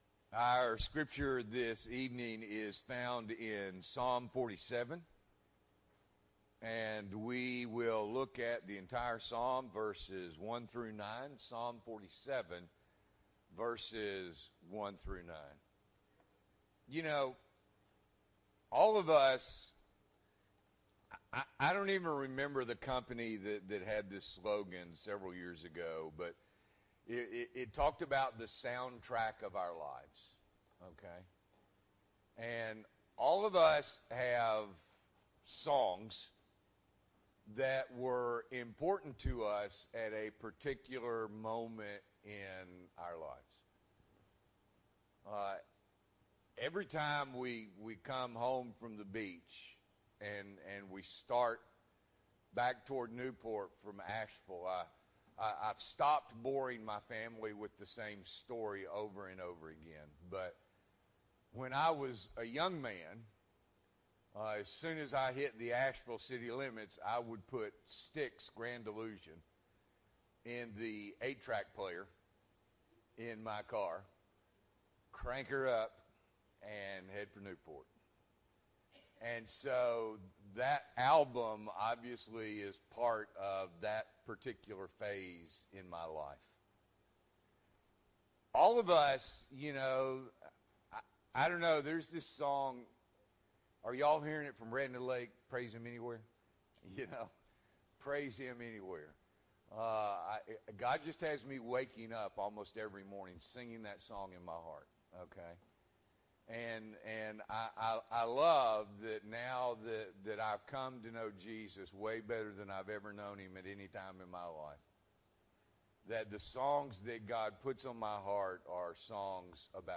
September 17, 2023 – Evening Worship